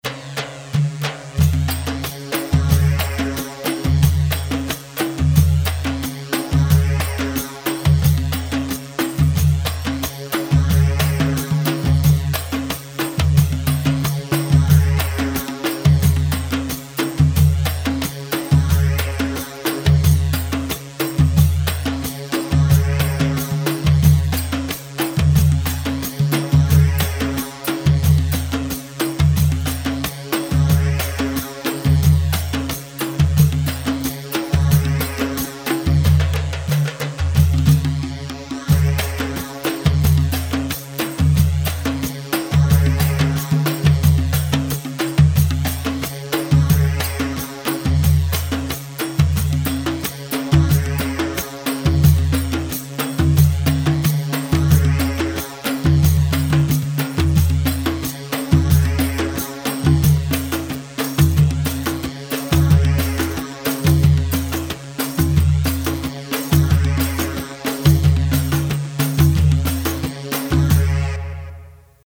Bandari 4/4 180 بندري
Bandari-4-4-180.mp3